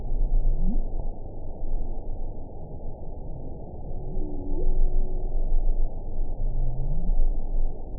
event 919328 date 12/30/23 time 20:00:31 GMT (1 year, 11 months ago) score 7.21 location TSS-AB07 detected by nrw target species NRW annotations +NRW Spectrogram: Frequency (kHz) vs. Time (s) audio not available .wav